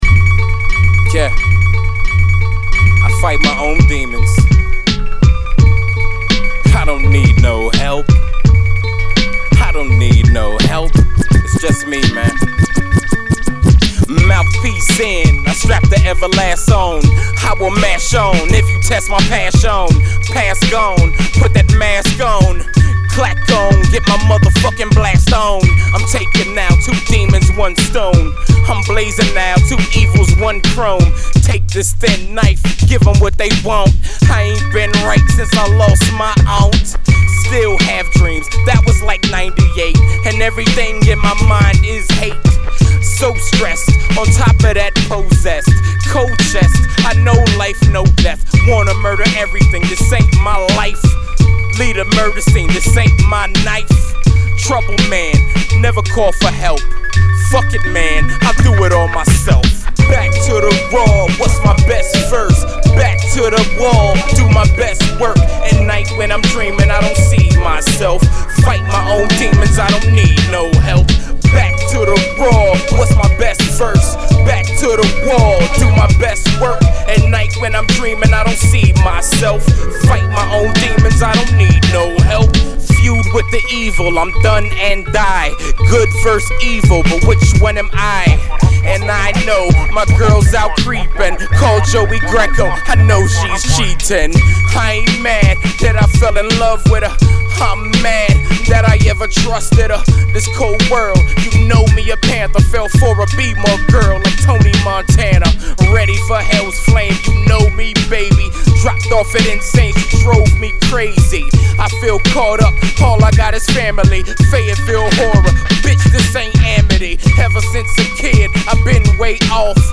pretty intense and personal track